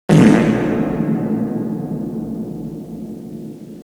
fart_w_reverb.wav